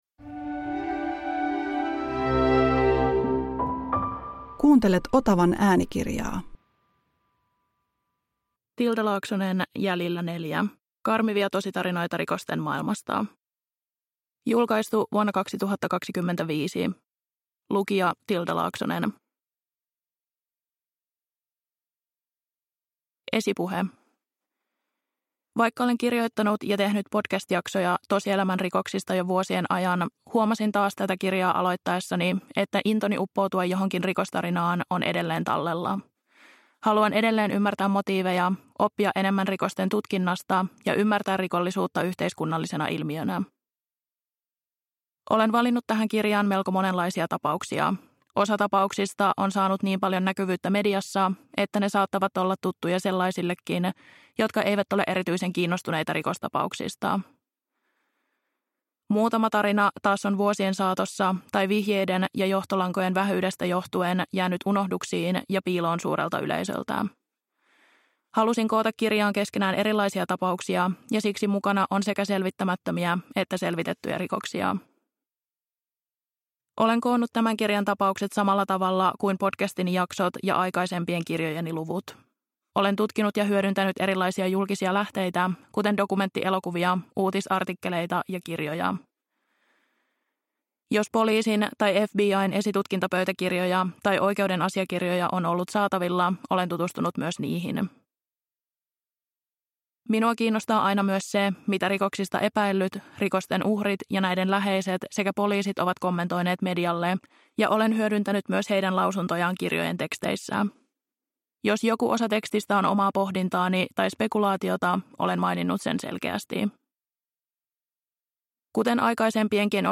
Jäljillä 4 (ljudbok) av Tilda Laaksonen